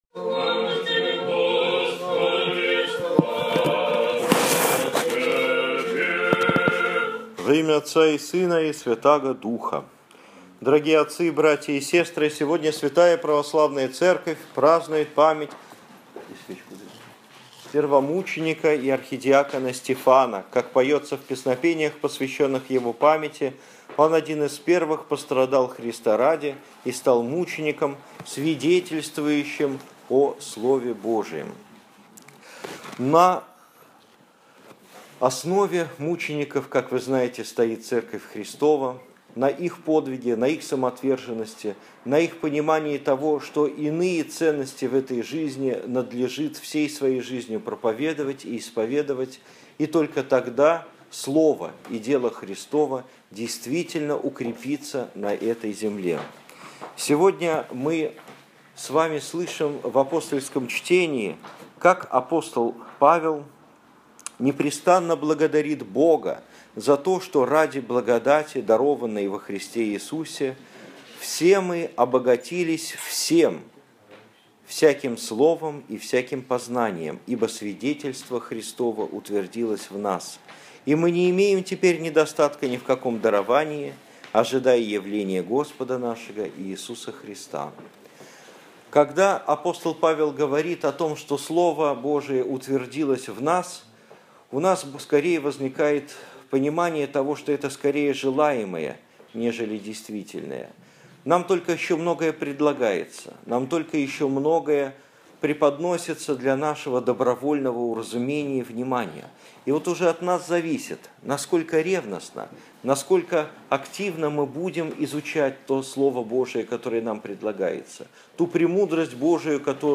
15 августа 2015 года. Суббота. Память св. архидиакона Стефана. Проповедь на литургии в храме свт. Луки
Домой / Проповеди / Аудио-проповеди / 15 августа 2015 года.